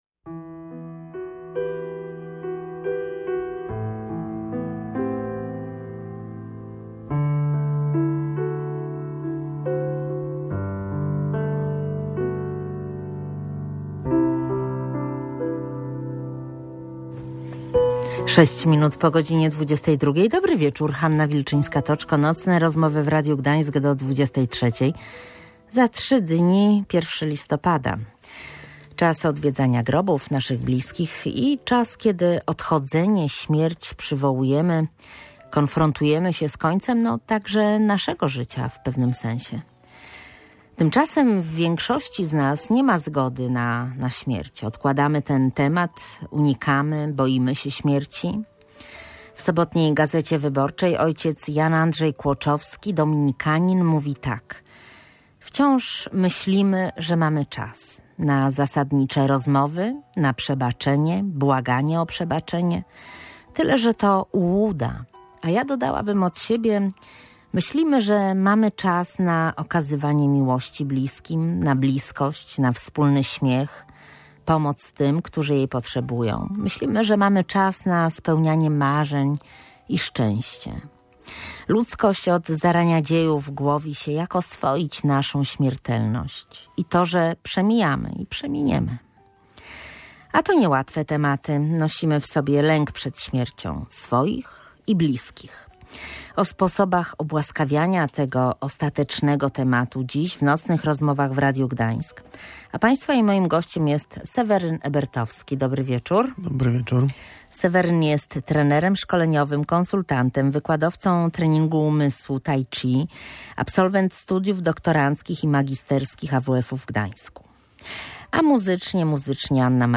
Jak zaakceptować fakt, że umrzemy? Rozmowy o przemijaniu